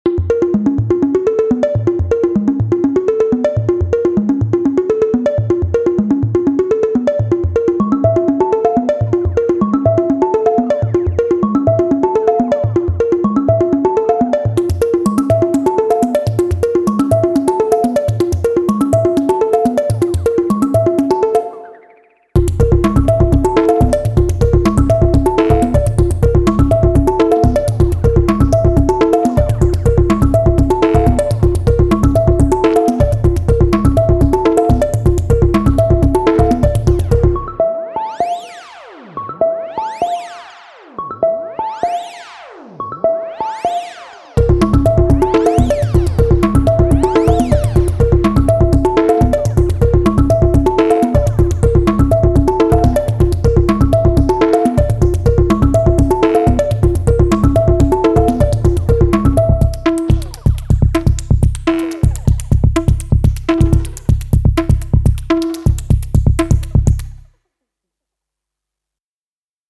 6x SY Toy, 15-step pattern.